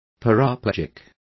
Complete with pronunciation of the translation of paraplegics.